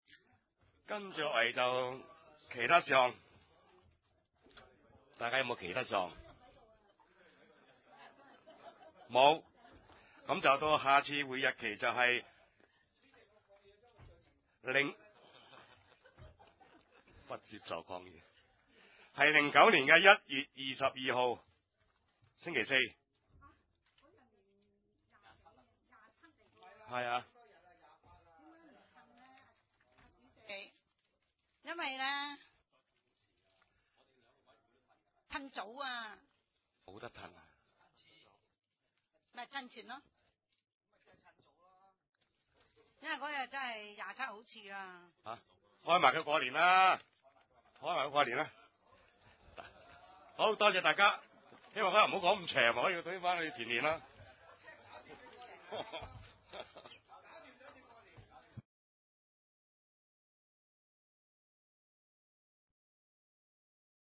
地點：深水埗區議會會議室